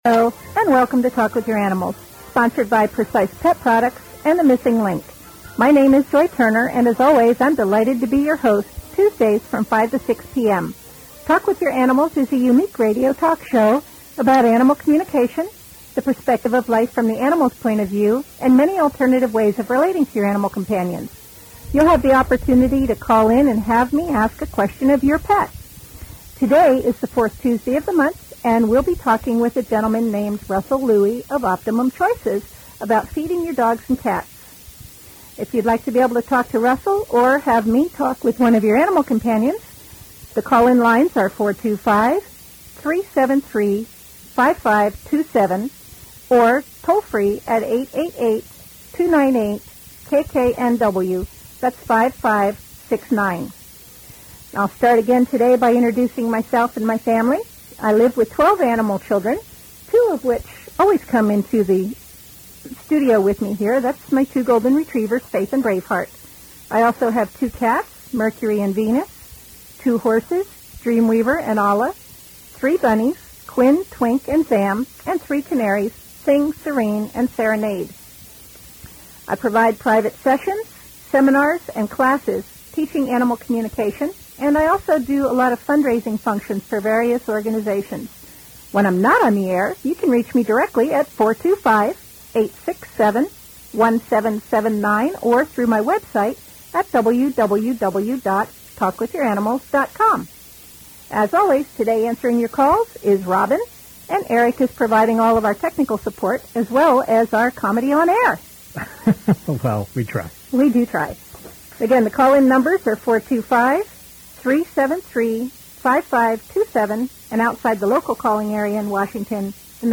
KKNW (1150 AM) Radio Show, Seattle